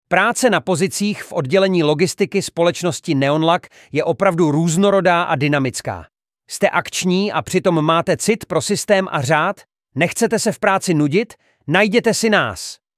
Práce v oddělení logistiky (audiospot)